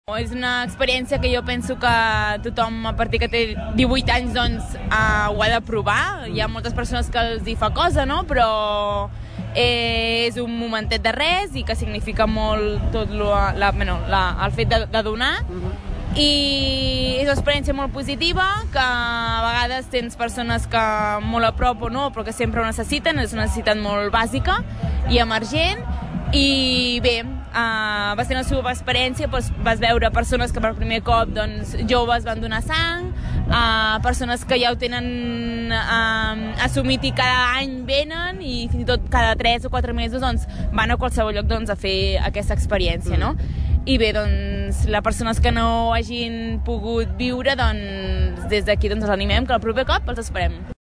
La regidora de sanitat de l’ajuntament de Tordera, Bàrbara Vergés, expressava les sensacions positives que els deixava aquesta marató, que han superat les dades de l’any anterior. Vergés també explicava com és la “superexperiència de donar sang”, lema de la campanya i animava, així, a seguir donant sang per salvar vides.